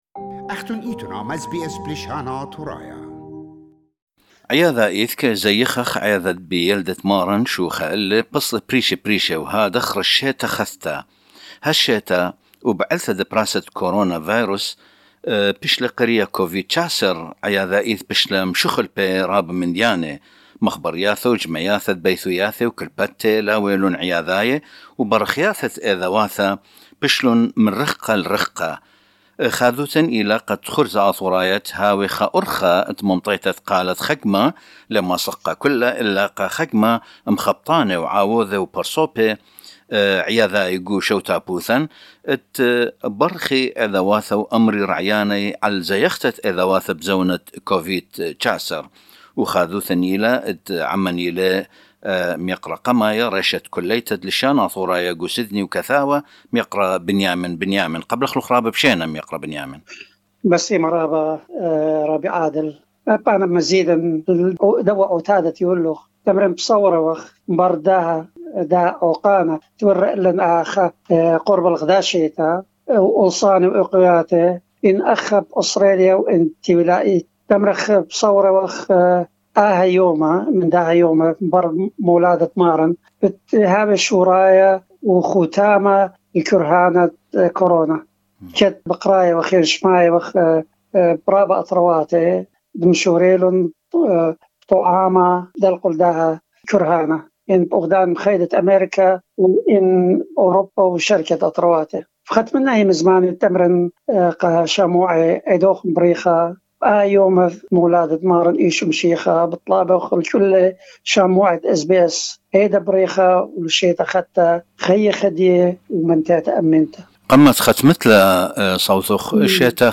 In several calls that SBS Assyrian did with some members of the community, all the interviewees shared the same opinion, that the instructions and rules that have been laid have helped further reduce spread of the virus.